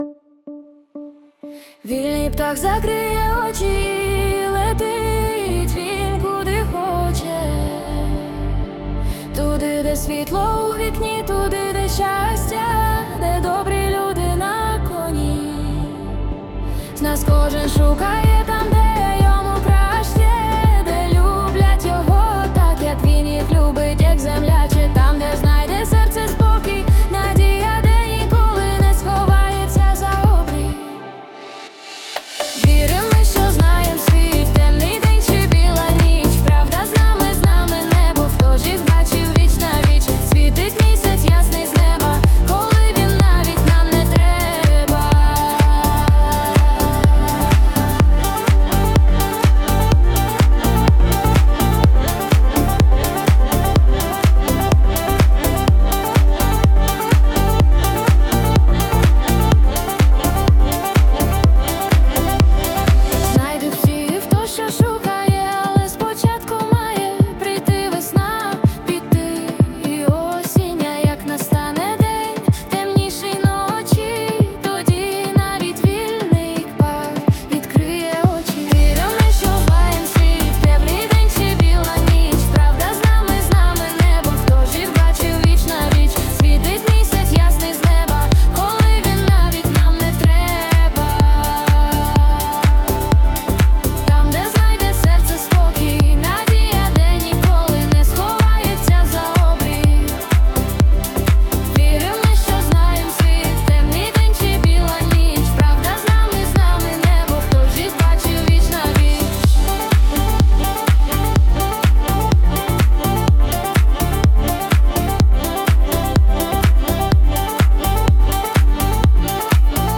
Вільний птах (+ 🎧музична версія)
СТИЛЬОВІ ЖАНРИ: Ліричний